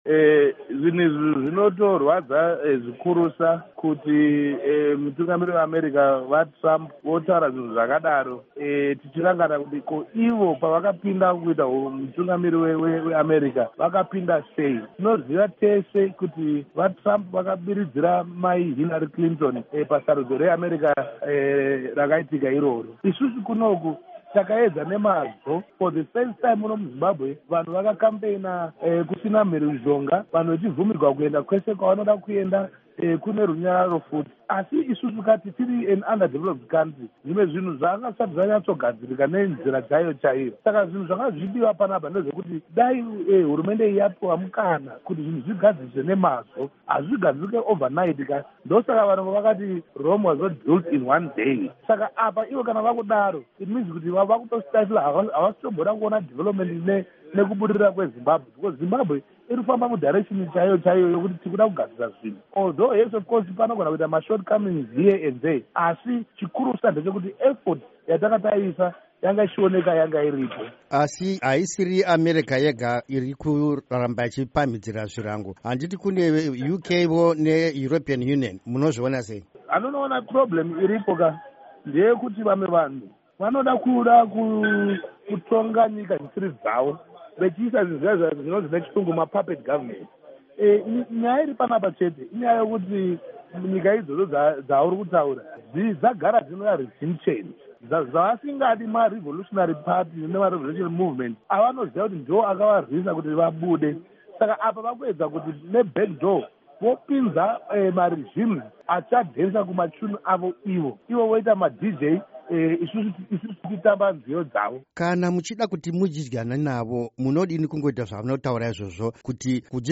Hurukuro naVaJoseph Tshuma